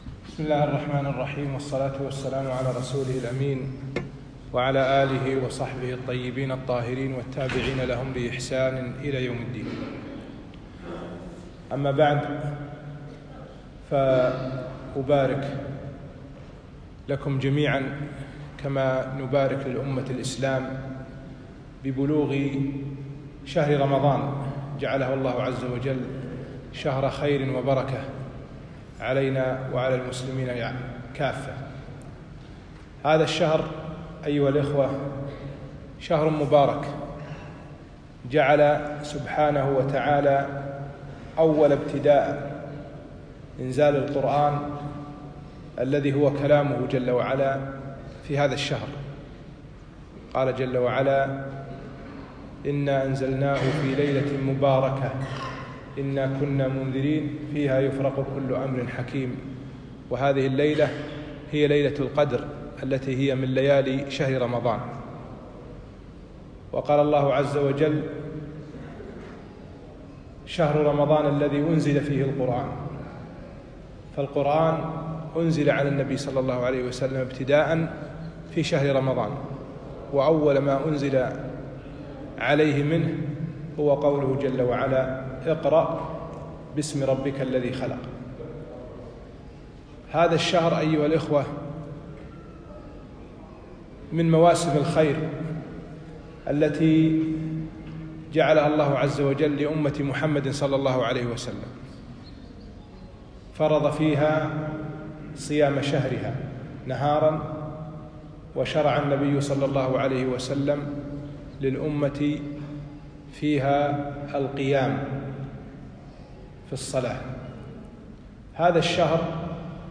محاضرة - مفسدات الصوم